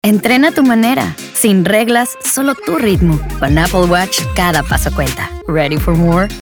Commercial
Confident - Girl Next Door